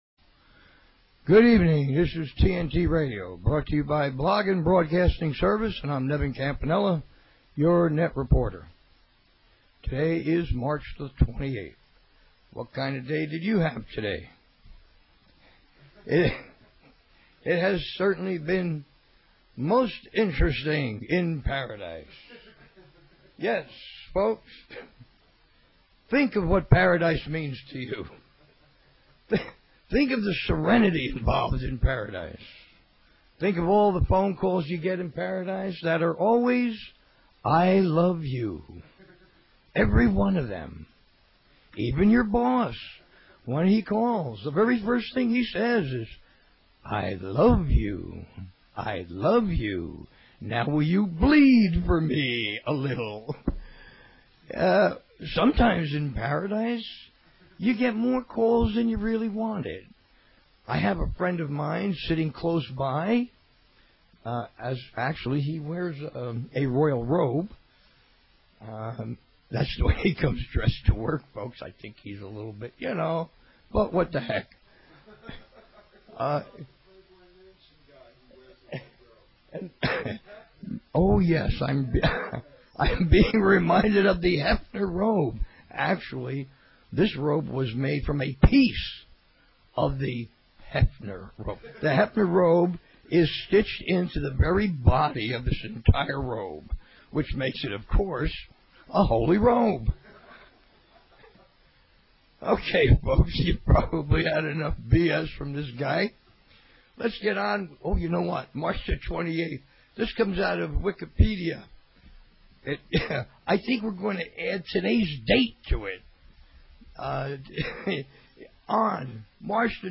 Talk Show Episode, Audio Podcast, TNT_Radio and Courtesy of BBS Radio on , show guests , about , categorized as